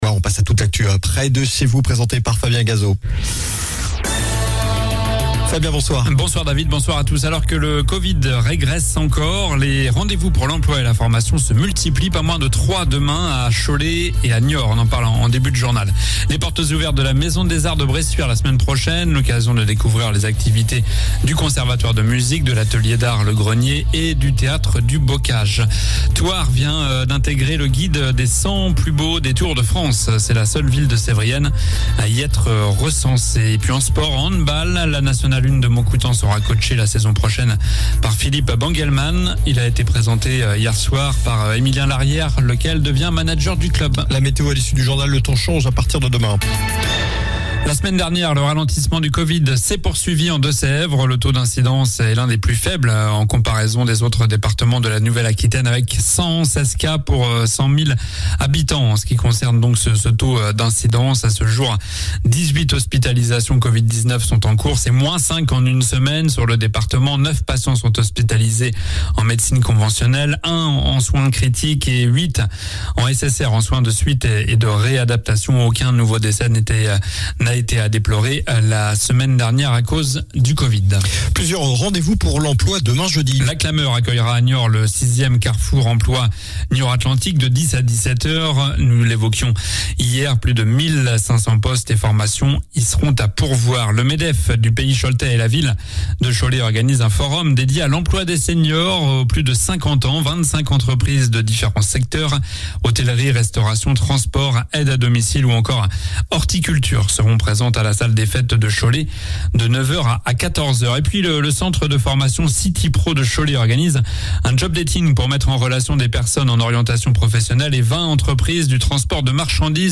Journal du mercredi 1er juin (soir)